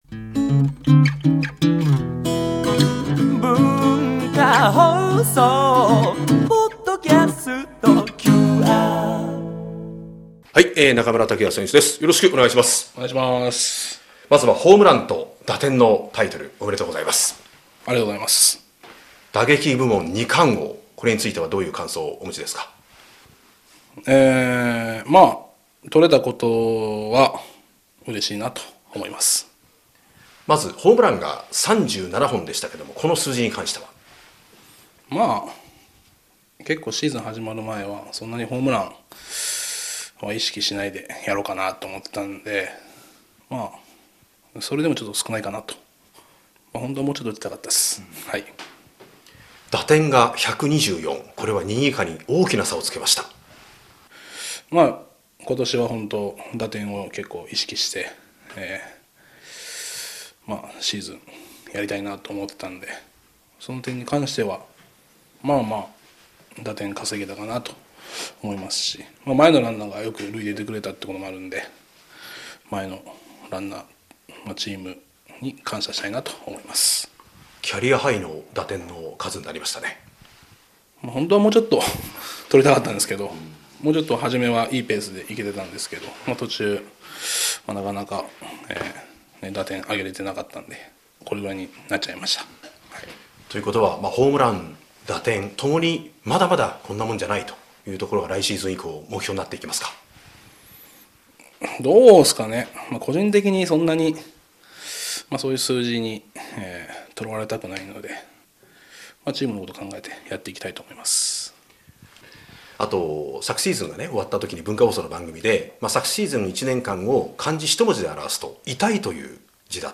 ◆タイトルホルダーインタビュー～中村剛也～